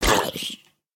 僵尸村民：受伤
Minecraft_zombie_villager_hurt1.mp3